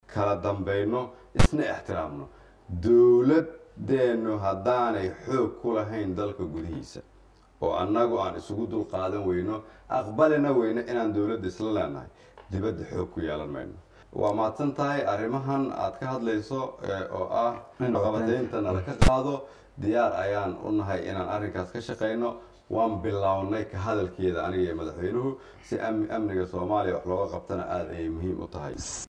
COD: Ra’isalwasaare Khayre oo ka hadlay qorshaha xayiraada hubka looga qaadayo Soomaaliya | Goobsan Media Inc